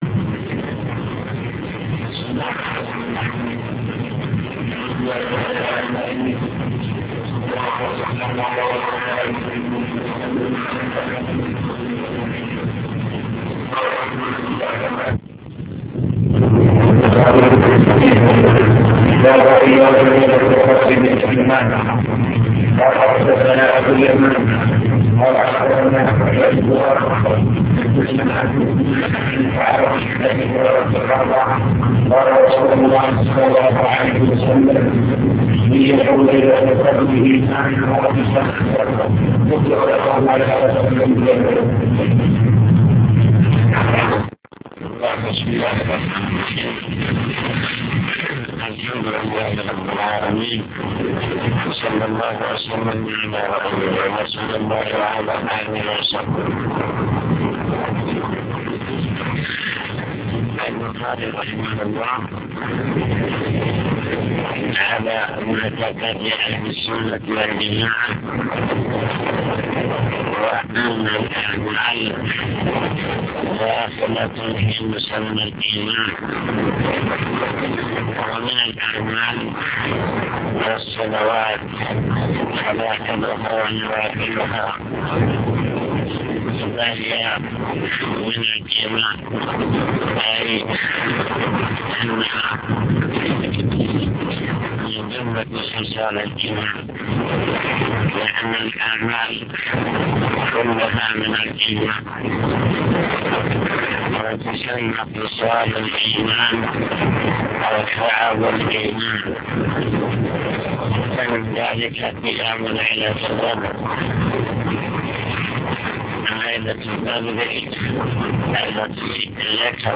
المكتبة الصوتية  تسجيلات - كتب  شرح كتاب الإيمان من صحيح البخاري